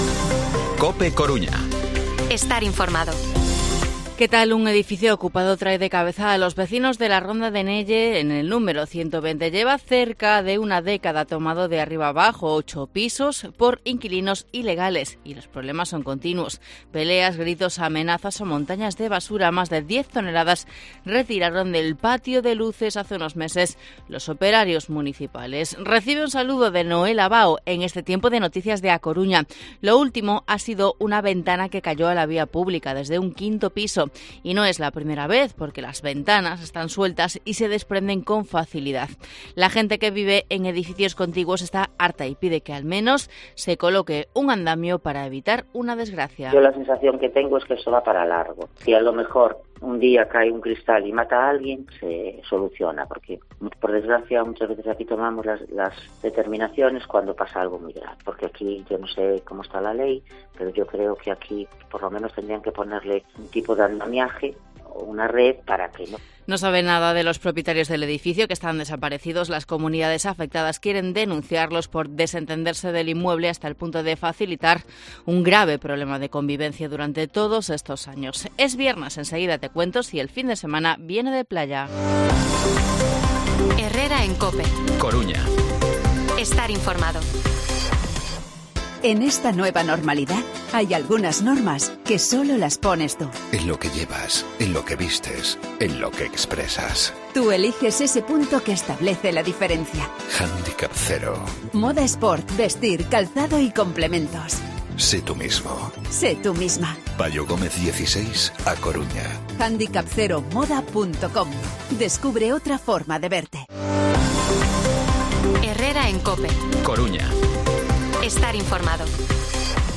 Informativo Herrera en COPE Coruña viernes, 4 de agosto de 2023 8:24-8:29